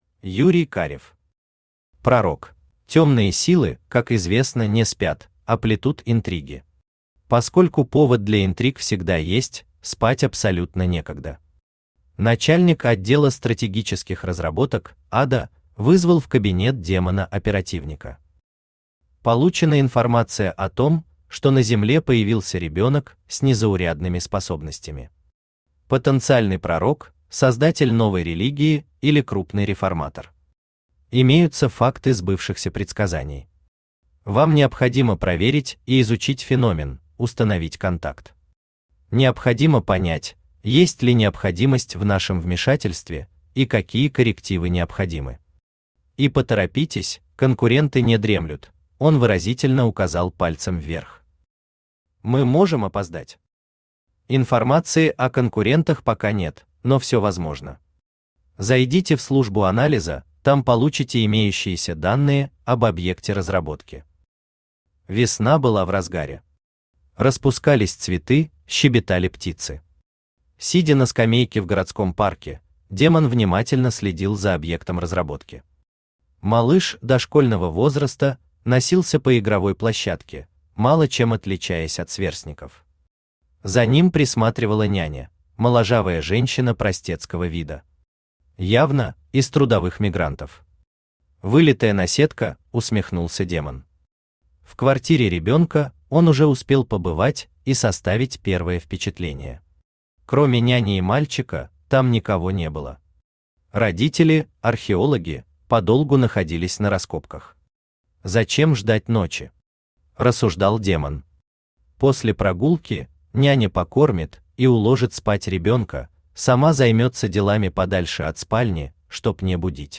Аудиокнига Пророк | Библиотека аудиокниг
Aудиокнига Пророк Автор Юрий Олегович Карев Читает аудиокнигу Авточтец ЛитРес.